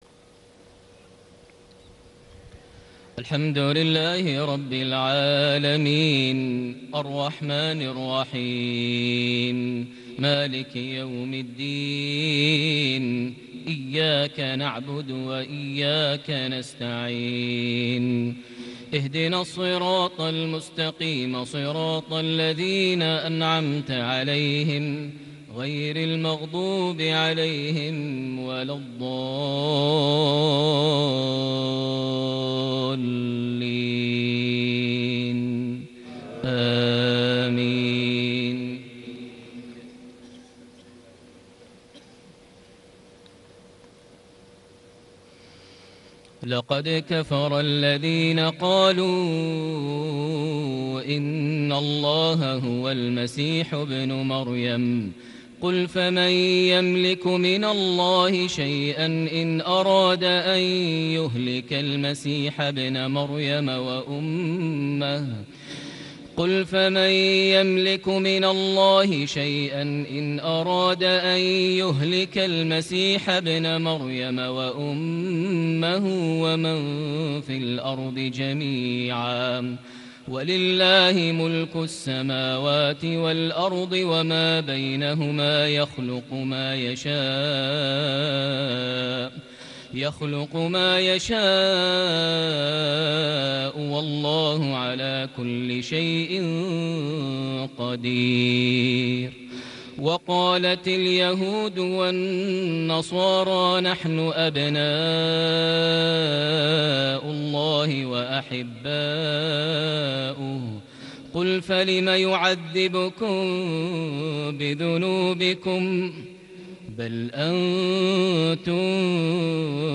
صلاة العشاء ٢٥ ذو القعدة ١٤٣٨هـ سورة المائدة ١٧-٢٦ > 1438 هـ > الفروض - تلاوات ماهر المعيقلي